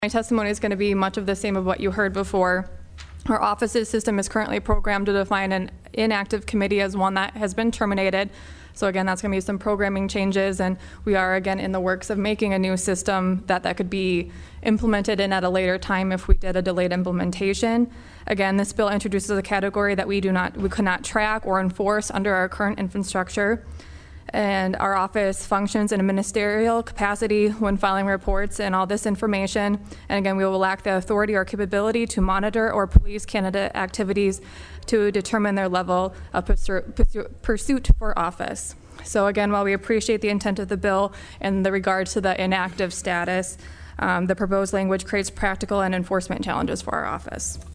PIERRE, S.D.(HubCityRadio)- District 1 Senator Michael Rohl presented SB17 to the South Dakota Senate State Affairs Committee on Wednesday.